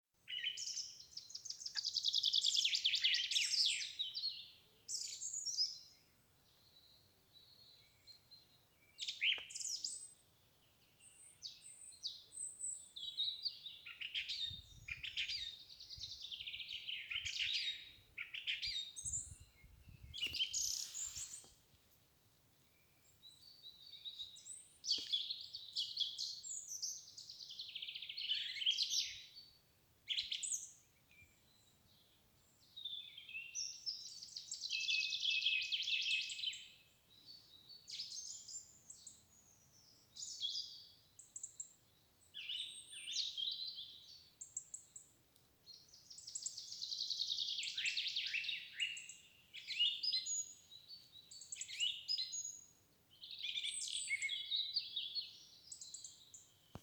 Song Trush, Turdus philomelos
Administratīvā teritorijaDundagas novads
StatusSinging male in breeding season